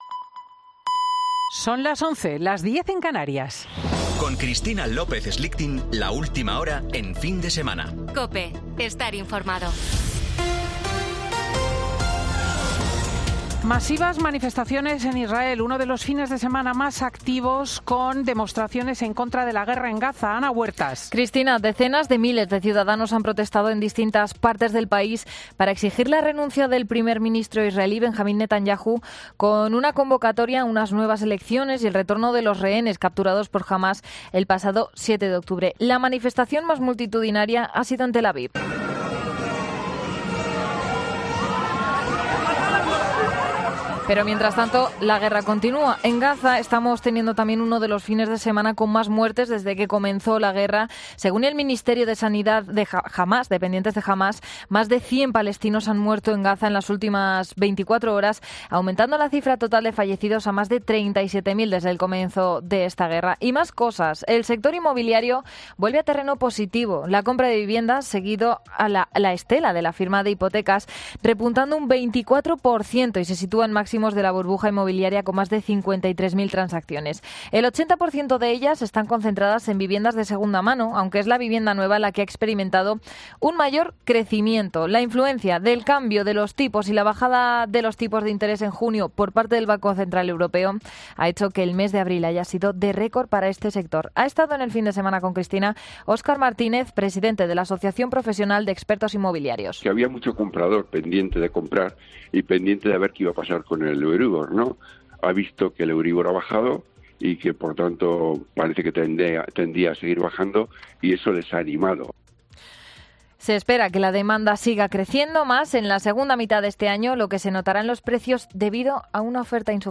Boletín 11.00 horas del 23 de junio de 2024